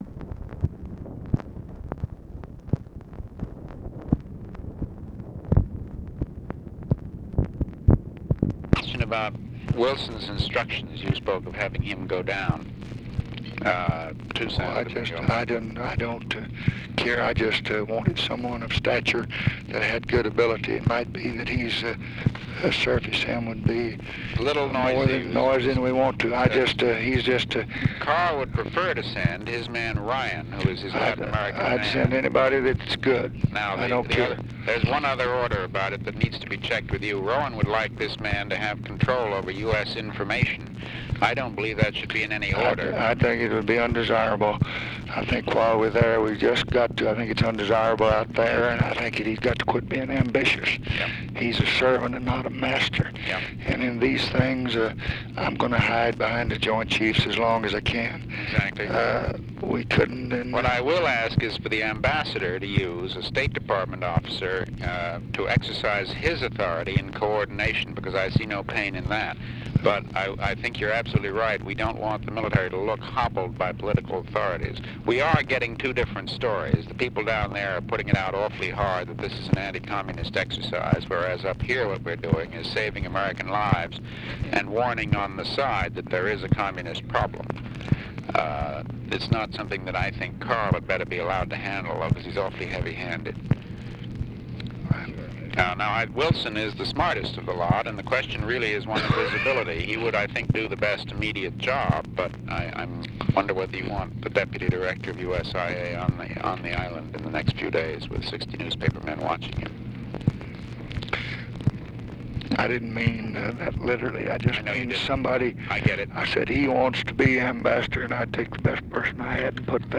Conversation with MCGEORGE BUNDY, May 1, 1965
Secret White House Tapes | Lyndon B. Johnson Presidency